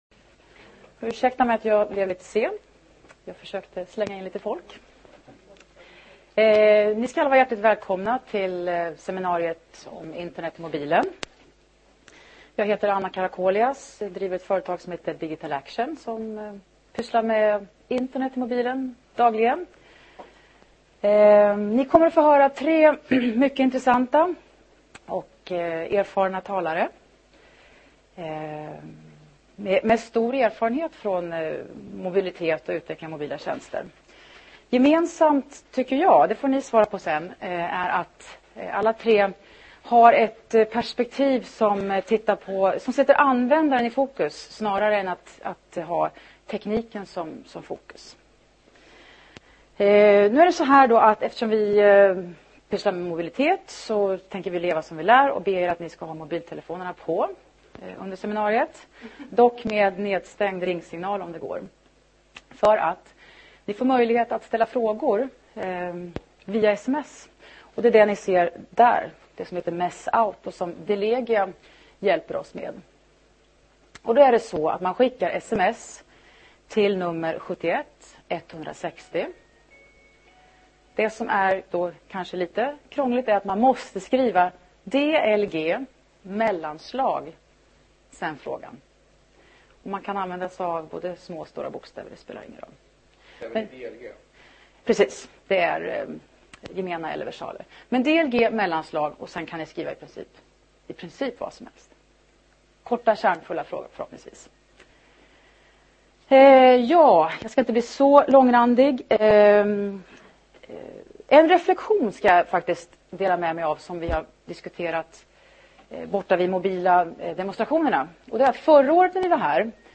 Ett mobilitetsseminarium som attackerar �mnet fr�n ett helt annat perspektiv - anv�ndarens. Vi vill ber�tta om och diskutera goda och v�l fungerande exempel.
Vi utlovar sp�nnande talare, f�ljt av m�jligheten att diskutera och st�lla fr�gor.